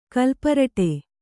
♪ kalparaṭe